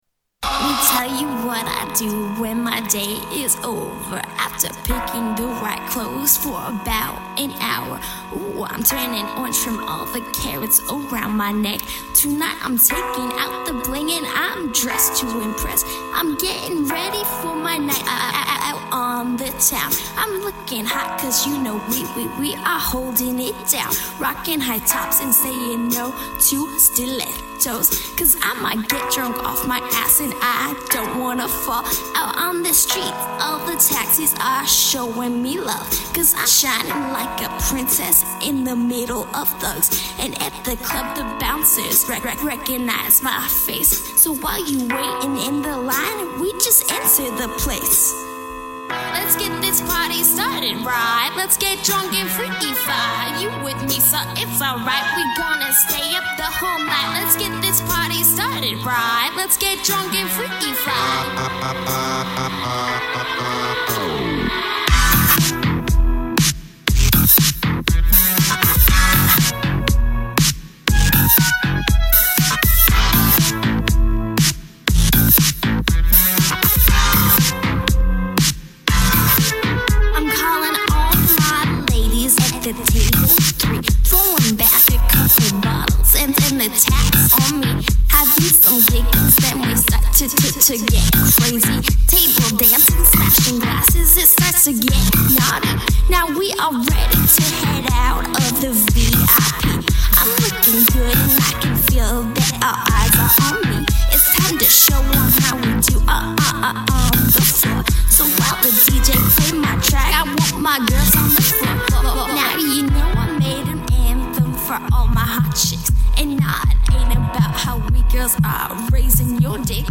Electro Rap